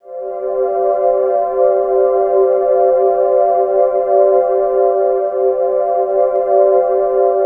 Index of /90_sSampleCDs/USB Soundscan vol.13 - Ethereal Atmosphere [AKAI] 1CD/Partition A/01-AMBIANT A